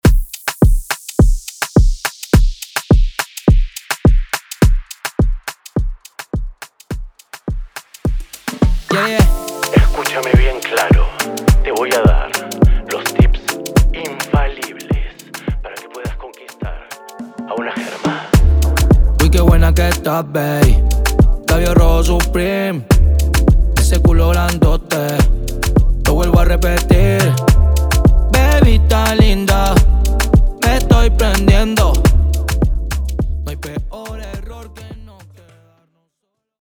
Latin music remixes